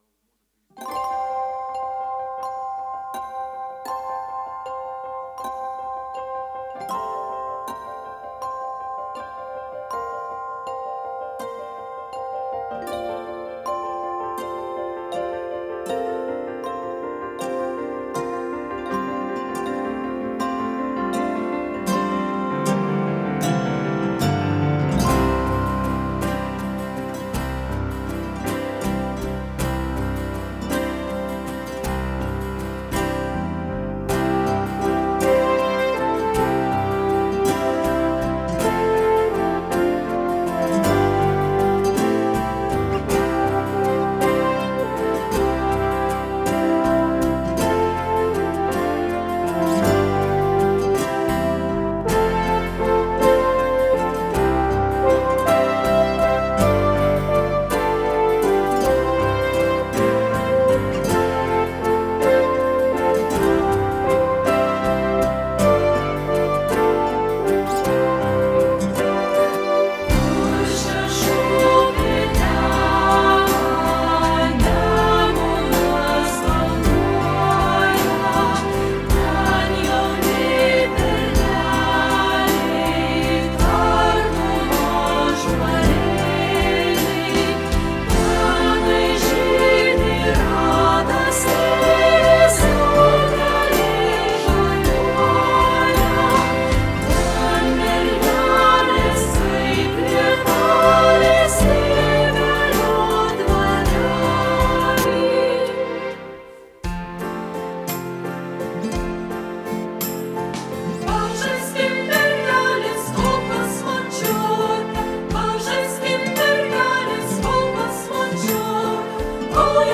Arūno Rakšnio išplėtota lietuvių liaudies muzika